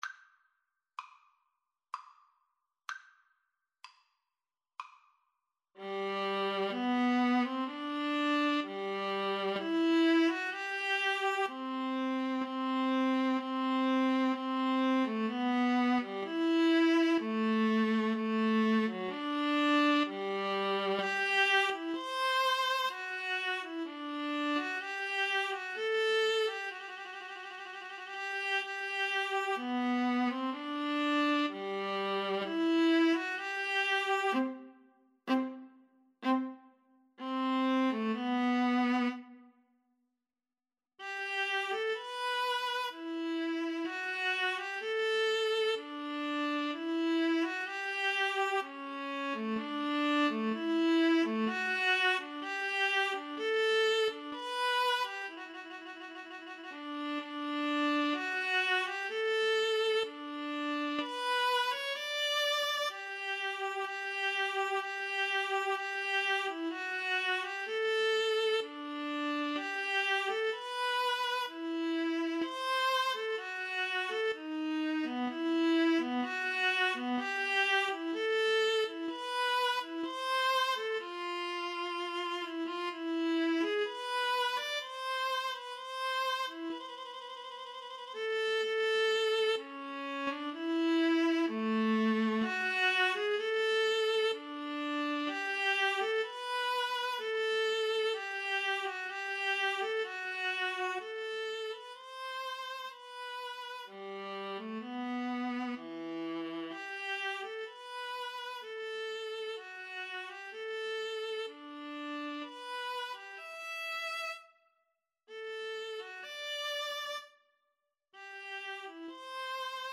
3/2 (View more 3/2 Music)
Classical (View more Classical Viola Duet Music)